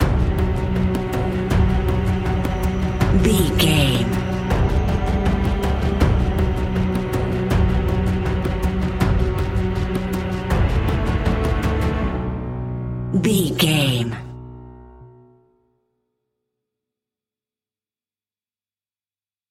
Thriller
Aeolian/Minor
ominous
dark
eerie
drums
synthesiser
piano
strings
horror music